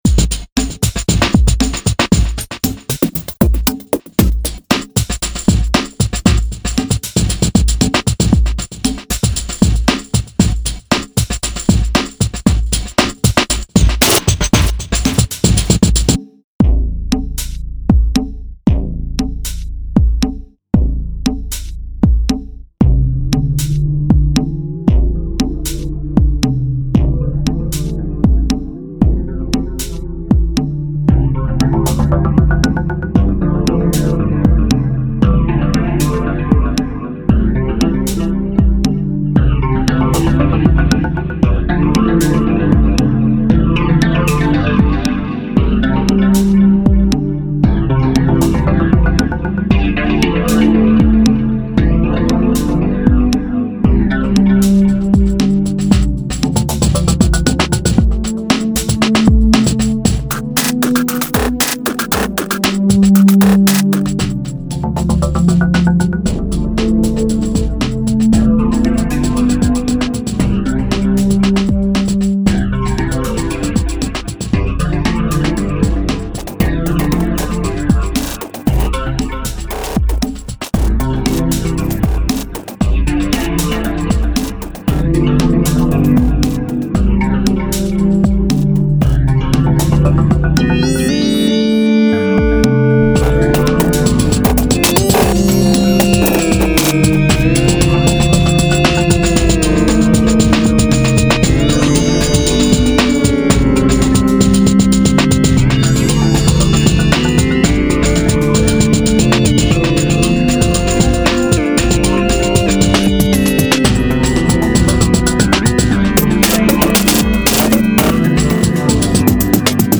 electronic ambient music
ambient music